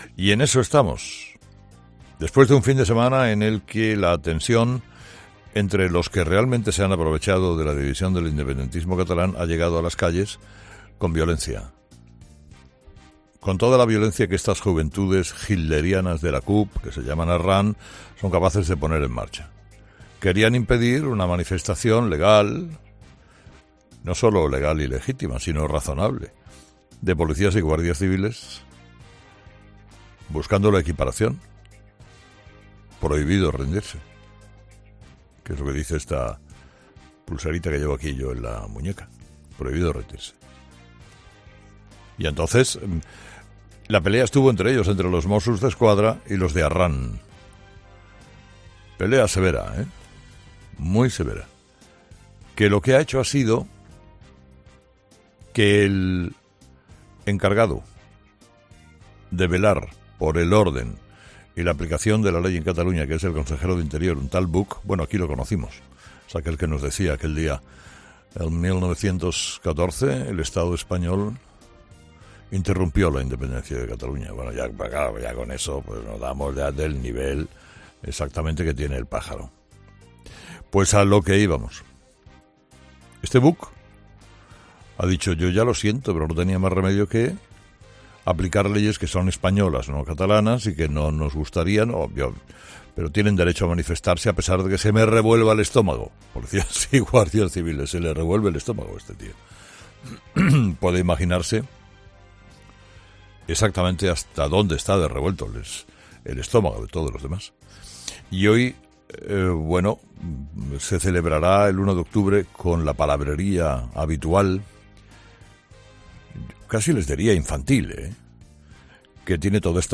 ESCUCHA AQUÍ EL EDITORIAL COMPLETO DE CARLOS HERRERA